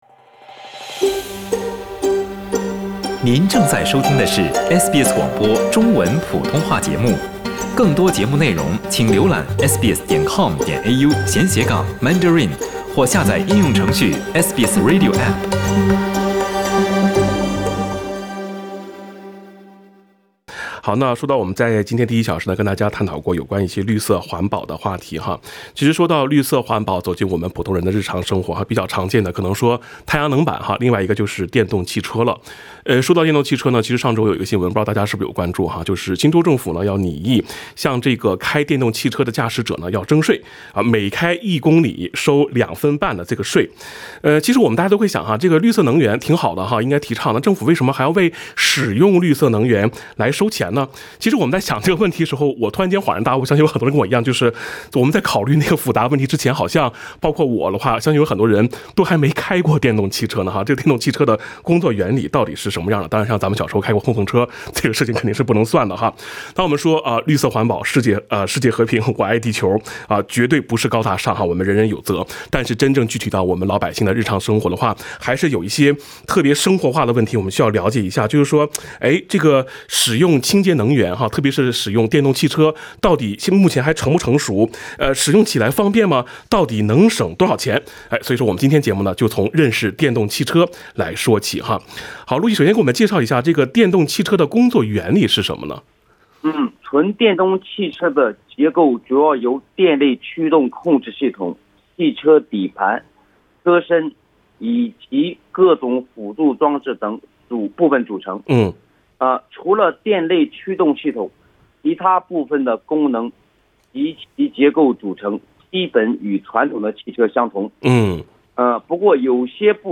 听众热线